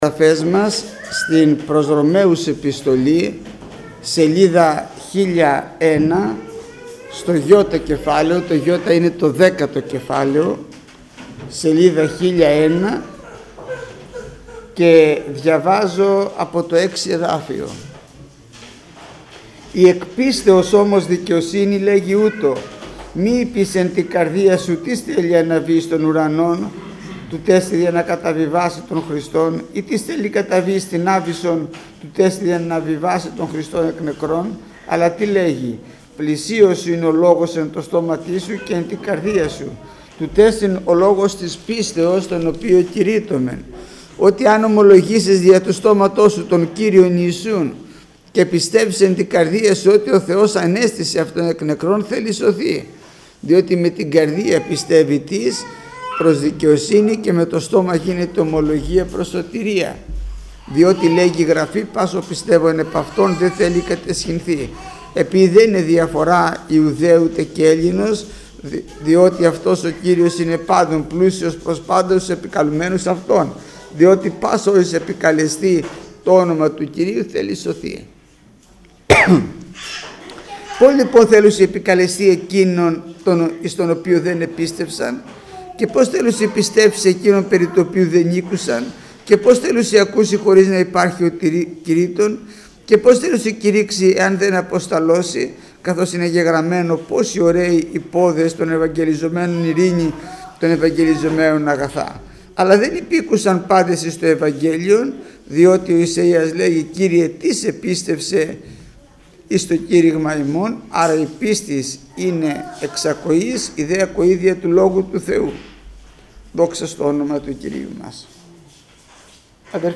Μήνυμα πριν τη θεία κοινωνία
ΜΗΝΥΜΑΤΑ ΠΡΙΝ ΤΗ ΘΕΙΑ ΚΟΙΝΩΝΙΑ